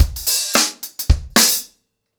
DaveAndMe-110BPM.5.wav